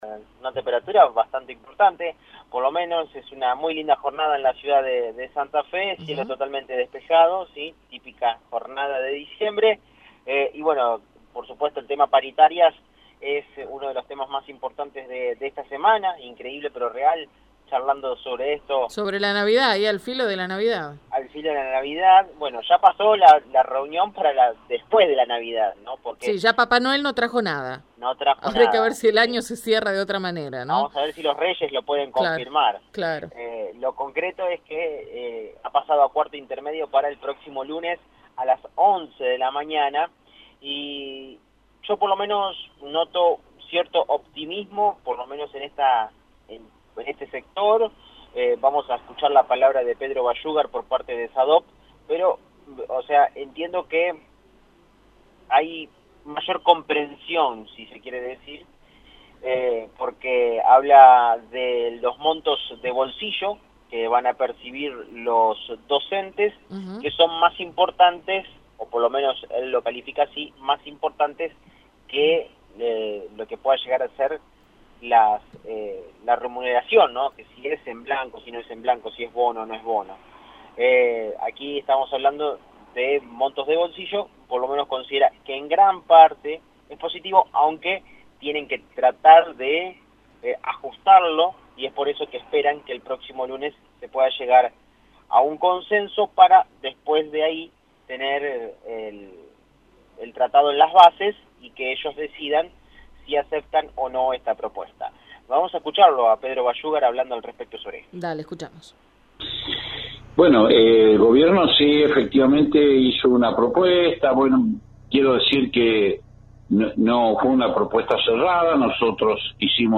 AUDIO DESTACADOProvinciales